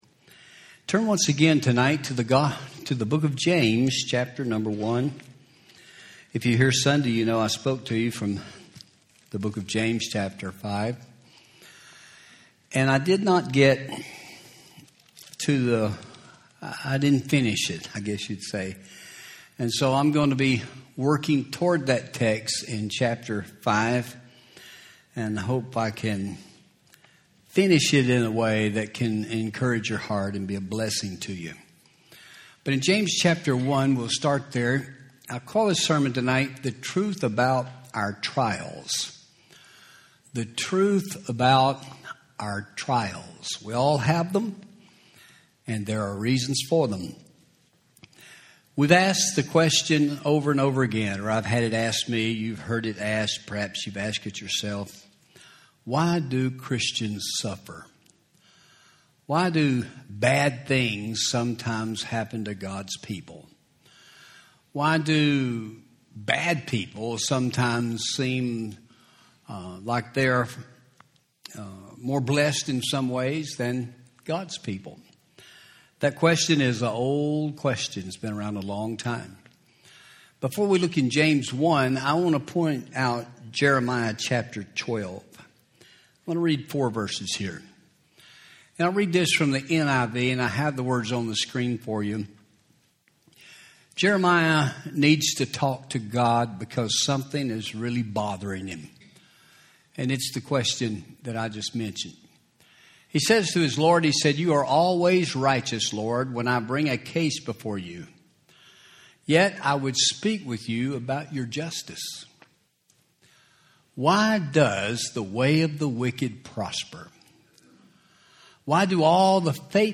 Audio Sermon